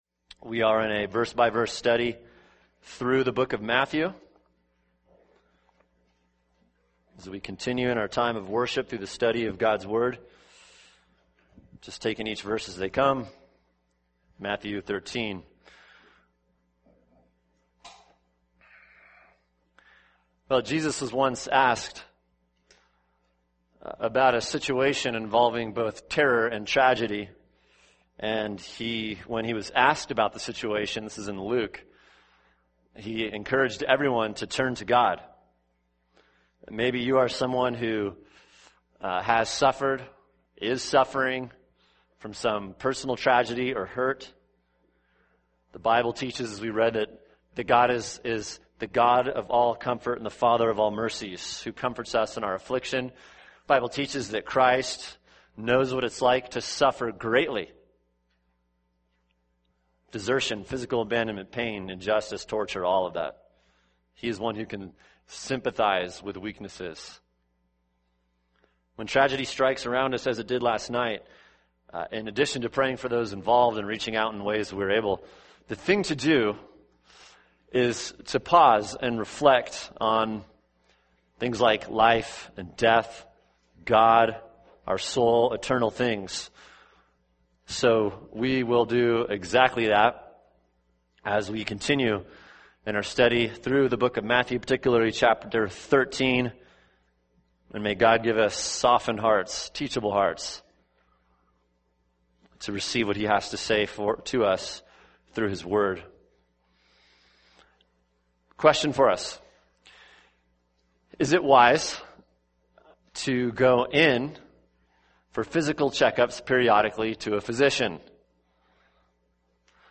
[sermon] Matthew 13:1-9, 18-23 – What is a Christian?